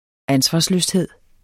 Udtale [ ˈansvɑsløsˌheðˀ ]